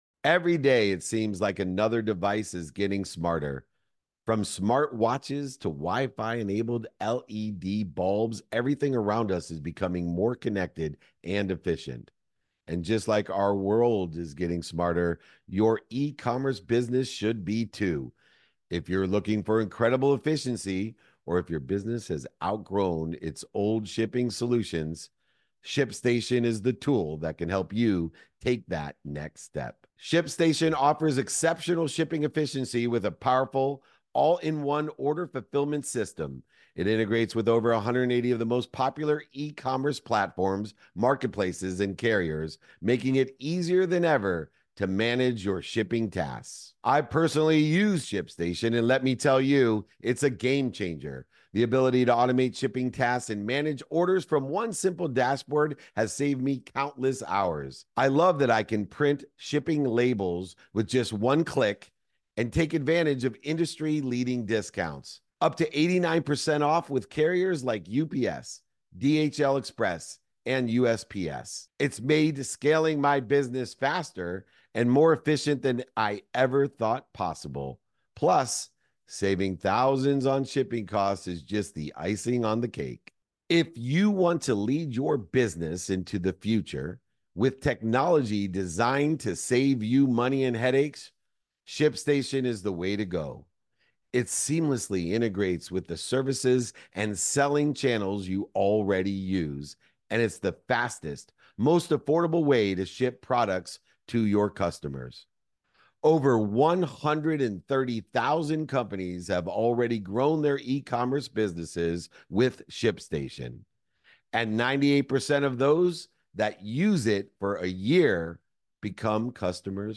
at a VIP dinner in Boston to discuss the profound impact Junior Achievement has on empowering the next generation. We explore how mentorship and intentional networking can shape young leaders into economic change-makers.